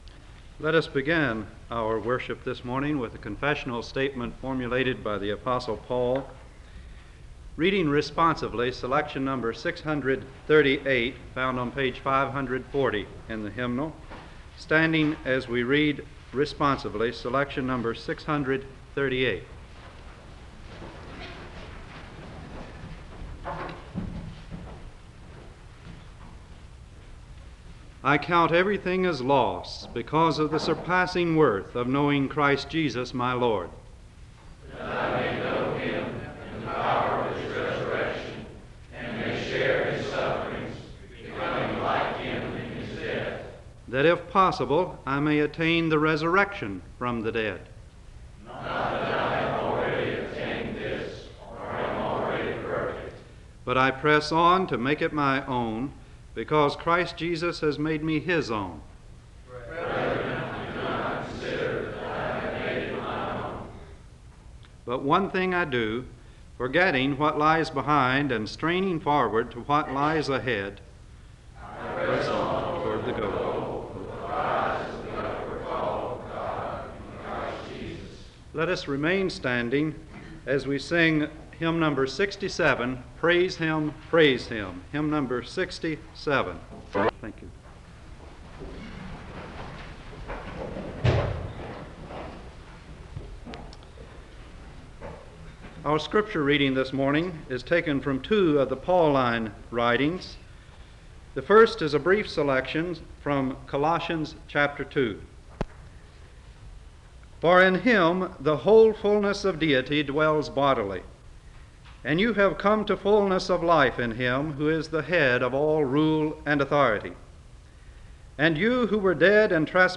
The service begins with a responsive reading (00:00-01:27).